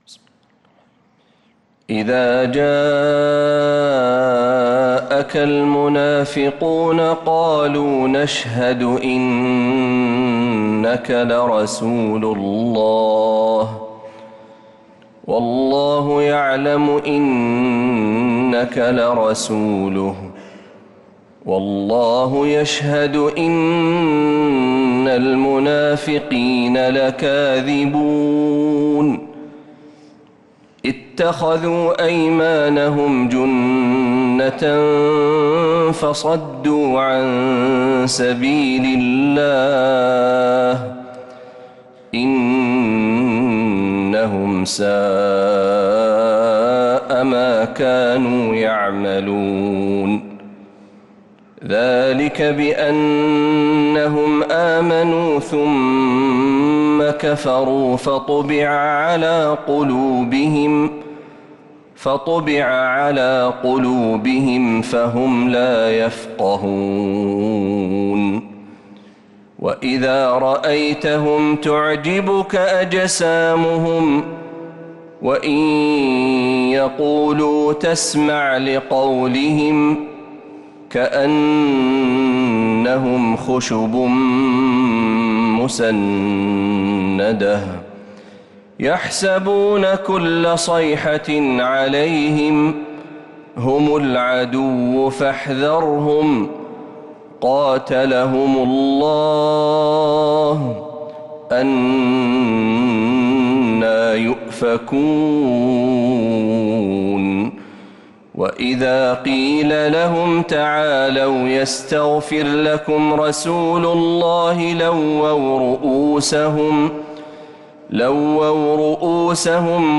سورة المنافقون كاملة من فجريات الحرم النبوي